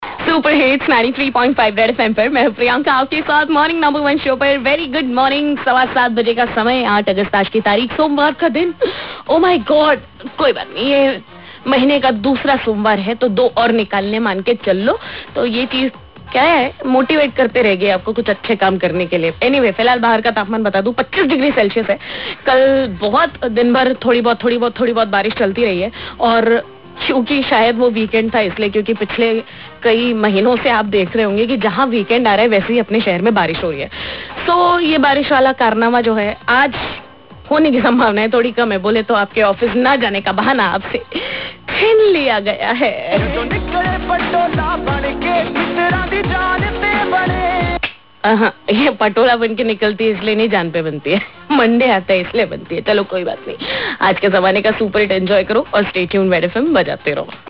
weather update